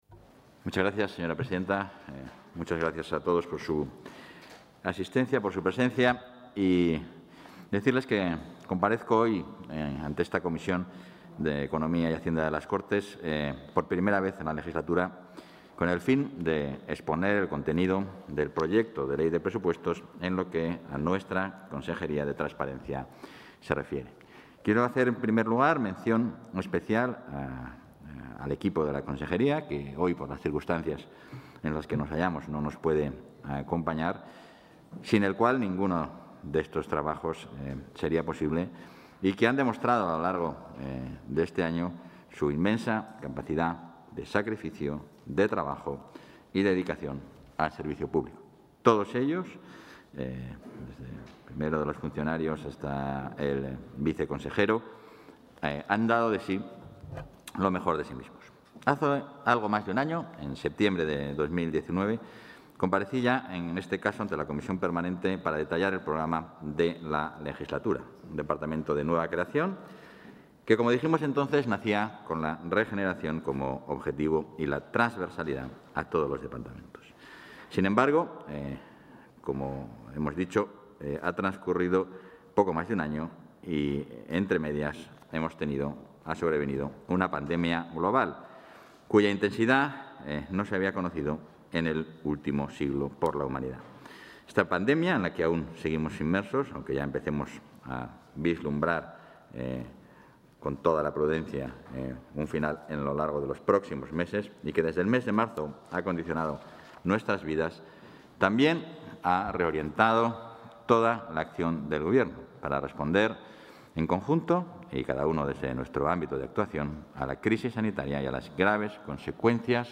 Intervención del vicepresidente y consejero de Transparencia, Ordenación del Territorio y Acción Exterior.